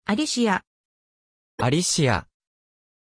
Pronunția numelui Alícia
pronunciation-alícia-ja.mp3